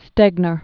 (stĕgnər), Wallace Earle 1909-1993.